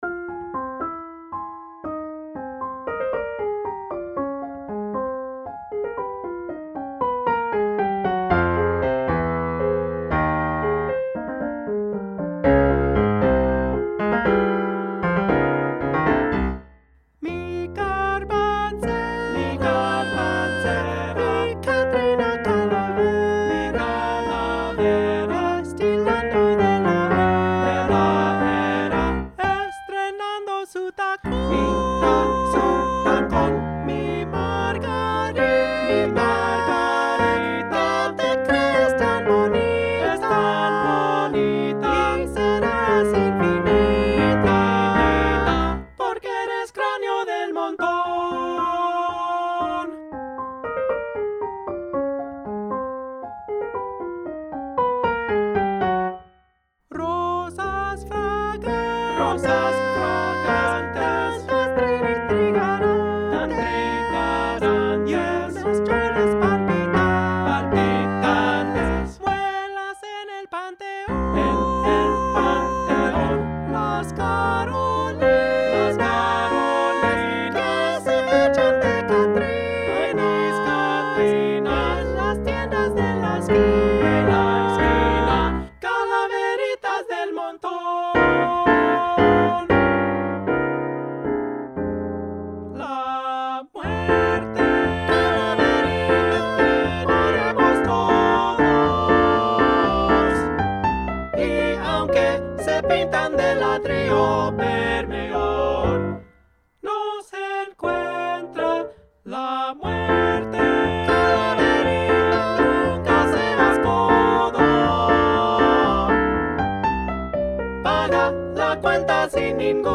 SSAA + Piano
SSAA, Piano